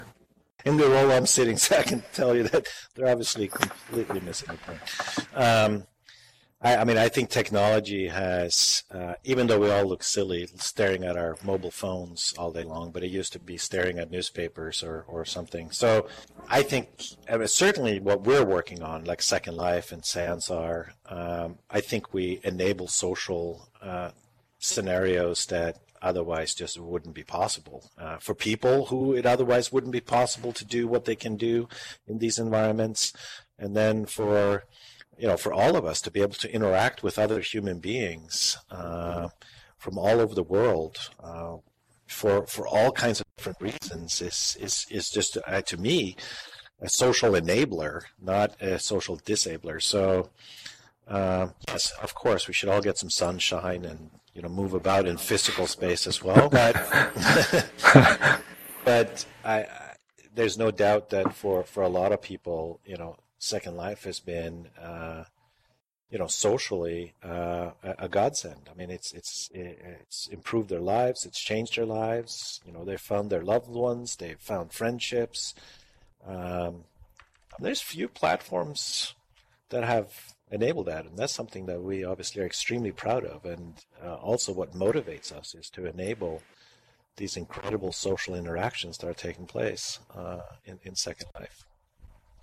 answering questions from hosts